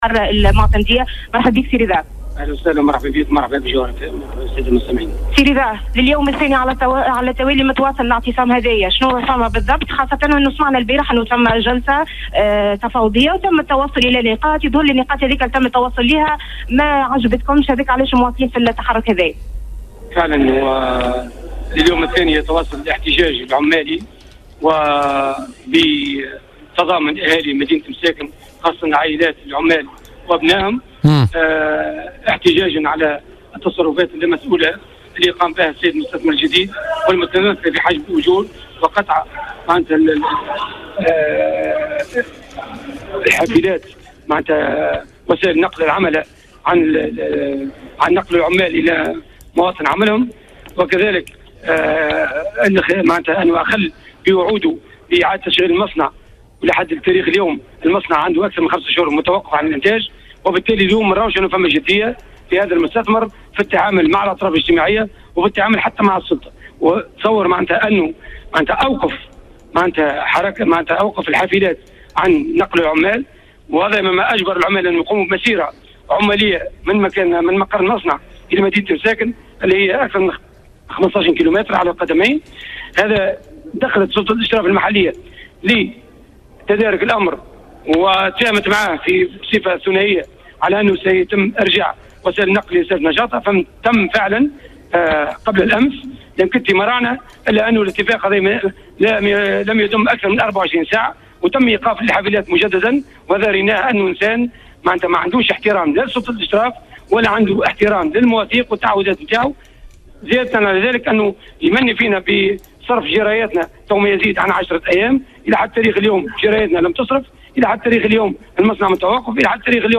من جهته أكد معتمد مساكن العربي قرطاس في مداخلة له في بوليتيكا أن درجة الإحتقان في مساكن بلغت اقصاها نتيجة التحرك الاحتجاجي لعمال ستيب .